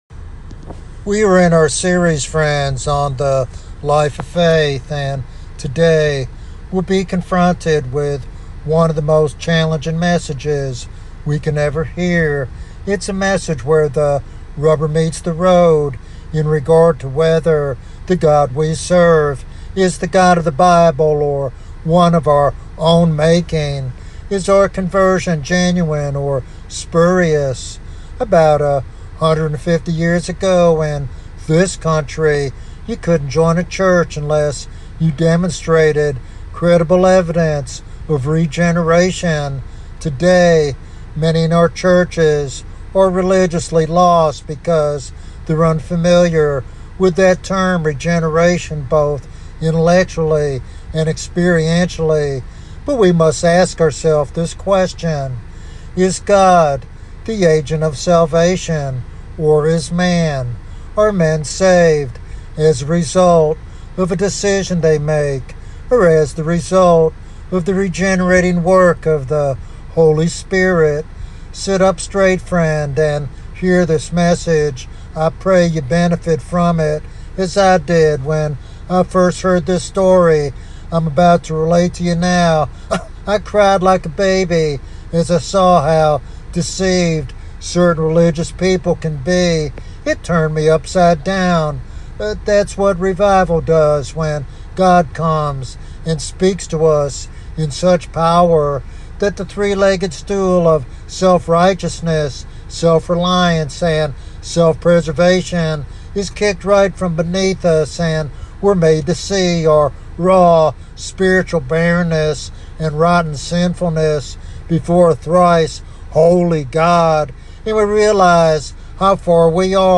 This sermon challenges listeners to examine their hearts and embrace the true salvation offered by Jesus Christ.